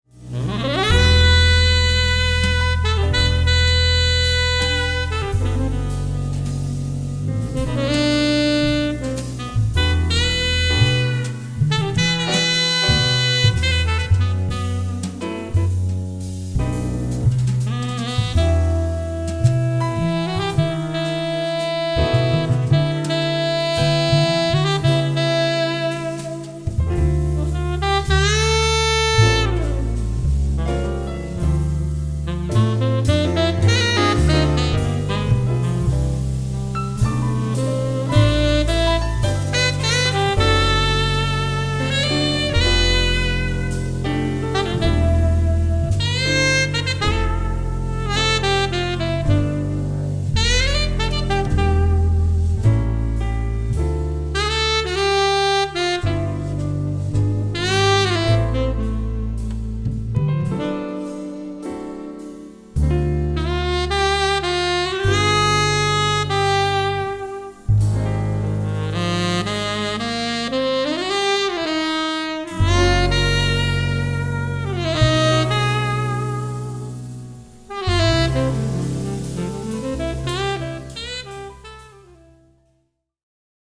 Soprano/Alto/Tenorsax/Altoflute
Piano/Organ/Rhodes
Double/Electric/Fretless Bass
Drums